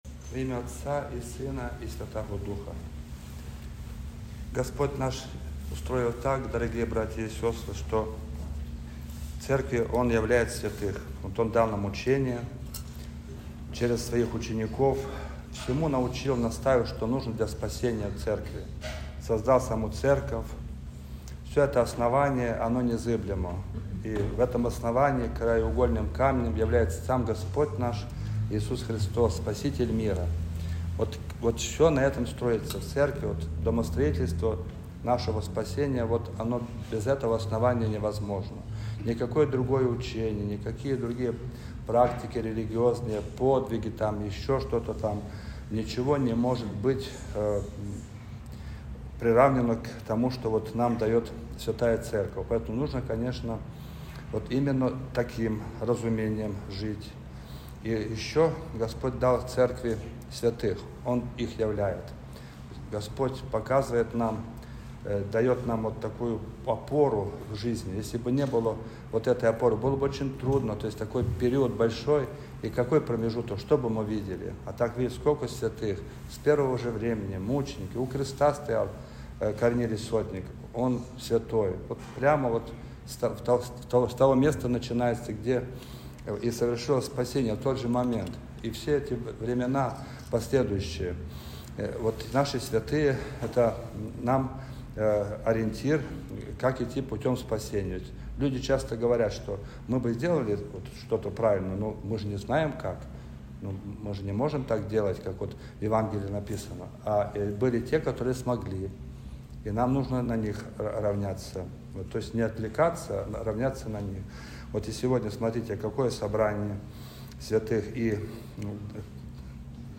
Проповедь
Божественная-литургия-4.mp3